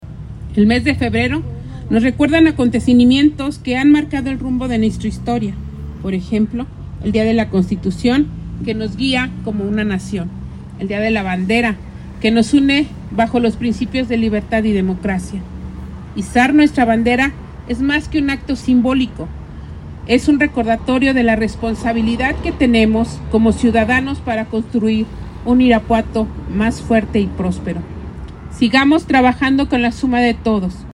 En esta ocasión, en el izamiento de la bandera monumental, estuvieron presentes autoridades y estudiantes de la primaria Emiliano Zapata, quienes participaron de manera entusiasta en la ceremonia y dieron a conocer las efemérides.
Joyce Guerrero Baraja, directora de Educación